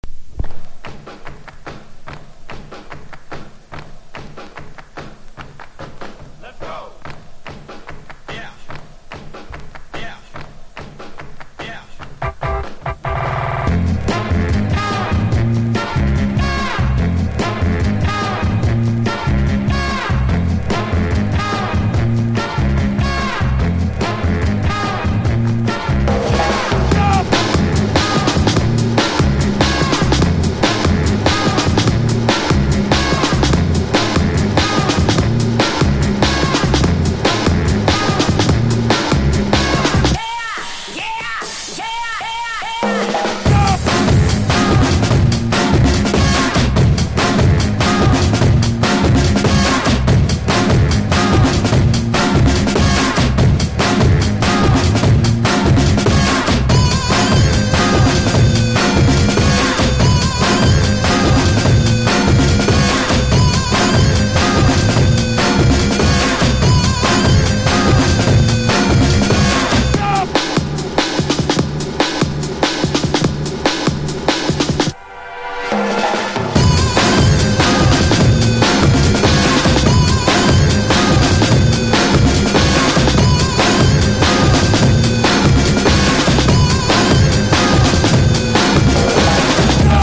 BREAK BEATS / BIG BEAT